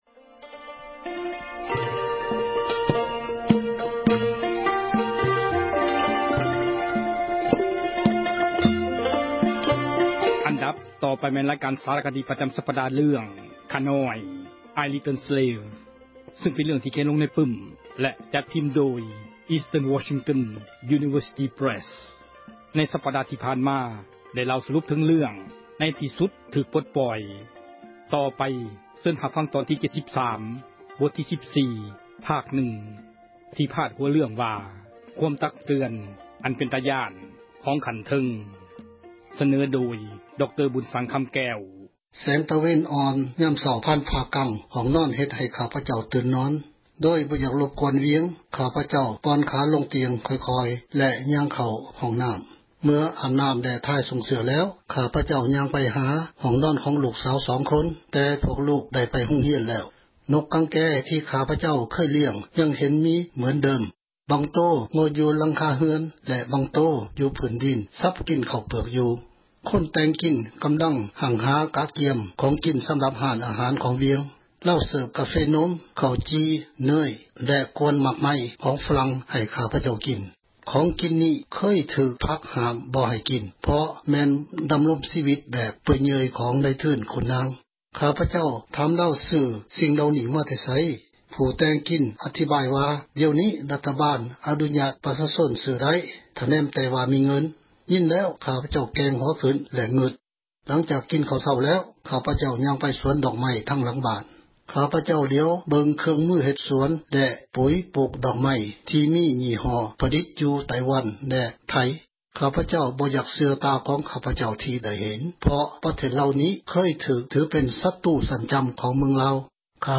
ສາຣະຄະດີ ເຣື່ອງ ”ຂ້ານ້ອຍ"